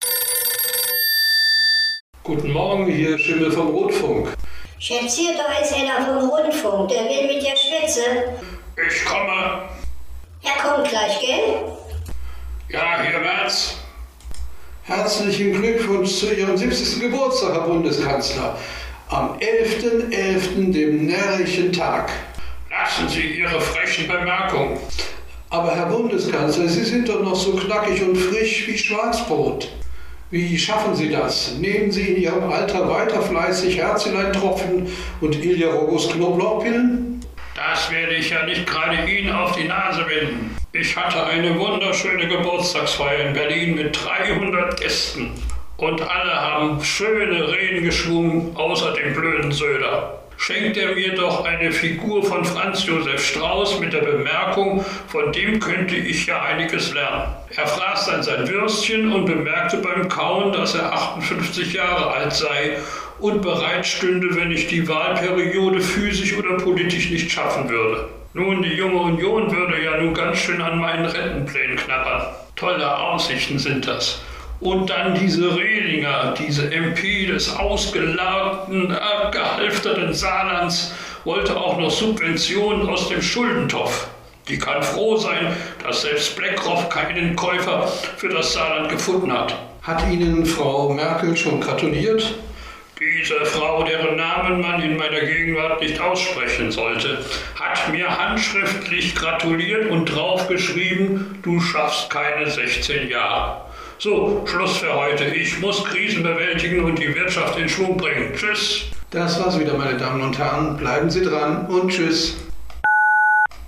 Merz Interview - 70. Geburtstag
Der ungeliebte Rotfunk interviewt Bundeskanzler Merz zu Hause und